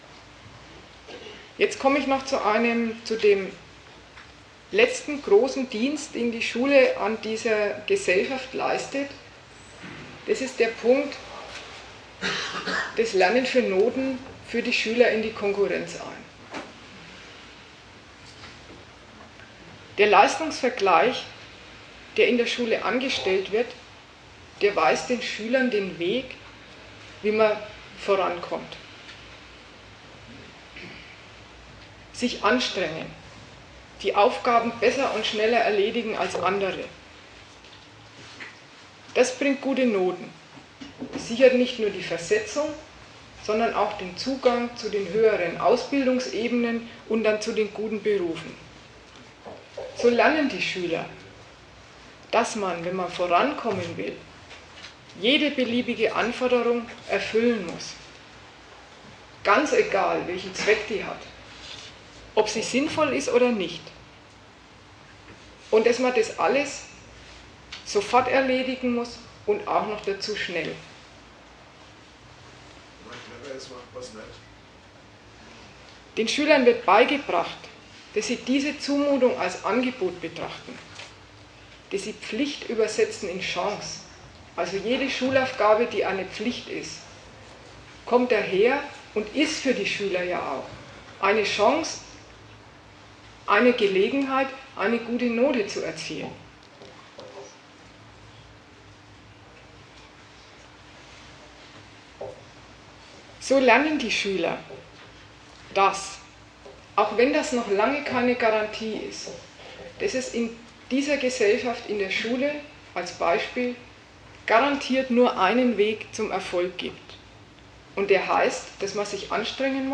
Datum 11.04.2013 Ort Nürnberg Themenbereich Wissenschaft und Ausbildung Veranstalter Sozialistische Gruppe Dozent Gastreferenten der Zeitschrift GegenStandpunkt Einerseits genießt die Schule den besten Ruf.